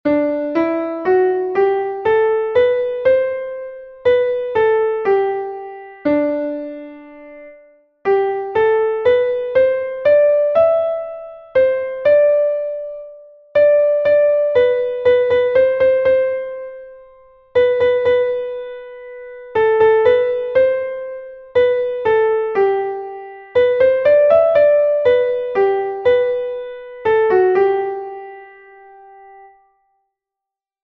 Exercise 2. Here, the pattern to practise is two semiquavers + a quaver slurred to a crotchet, along with a time signature change (6/8 + 2/4), starting in ternary subdivision and shifting to binary subdivision (6/8 + 2/4) with the equivalence: dotted crotchet = crotchet.
Rhythmic reading 1